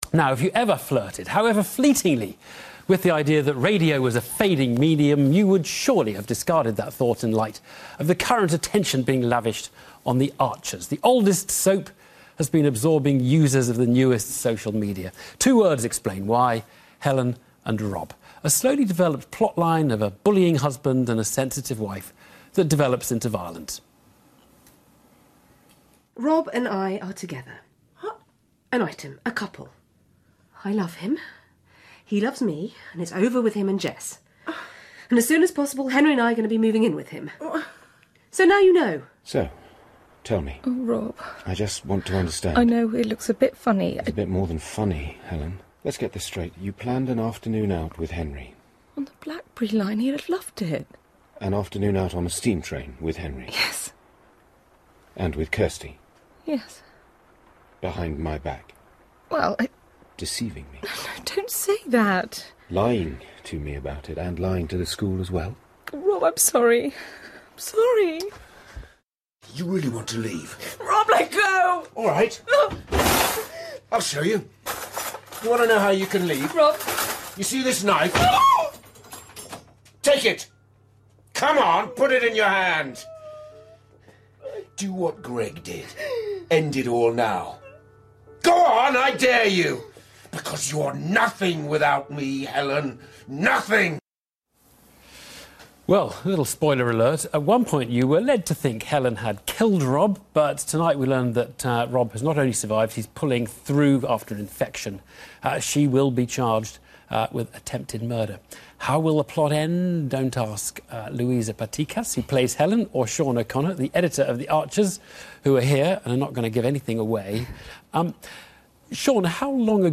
Here, the plot makes Newsnight coverage.